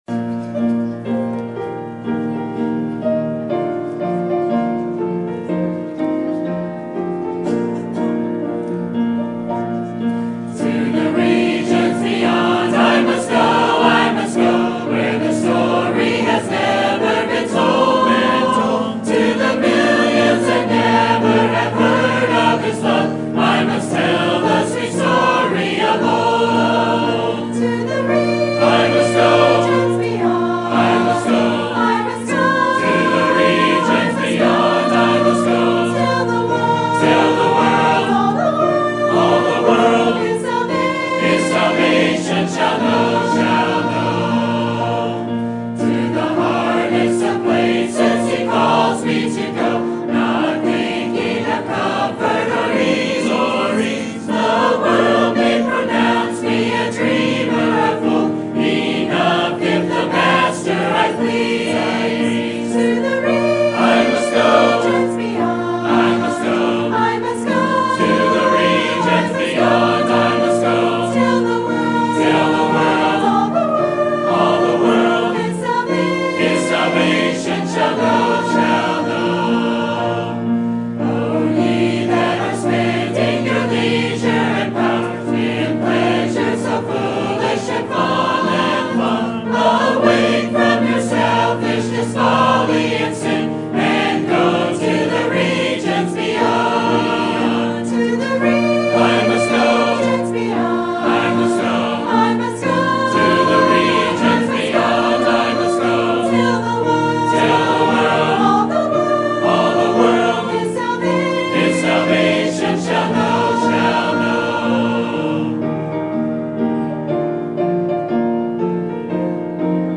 Sermon Date
Sermon Topic: Missions Conference Sermon Type: Special Sermon Audio: Sermon download: Download (22.57 MB) Sermon Tags: Luke Jesus Missions Trust